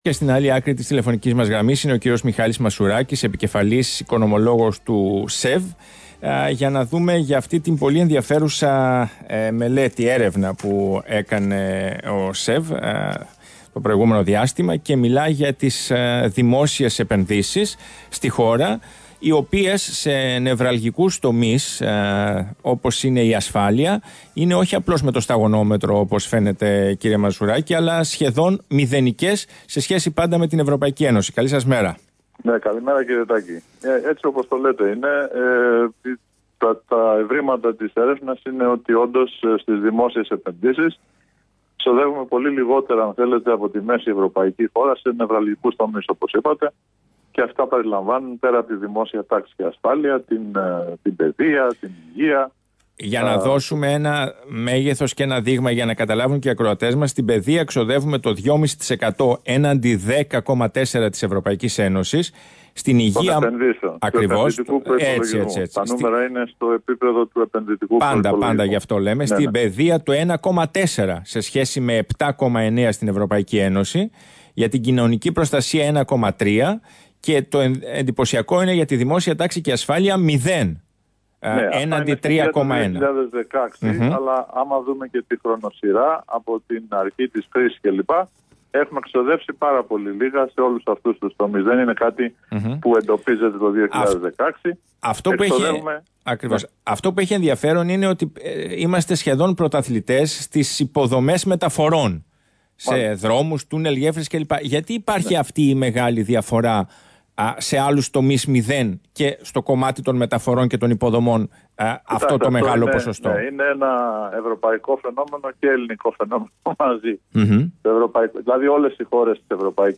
στα ΠΑΡΑΠΟΛΙΤΙΚΑ FM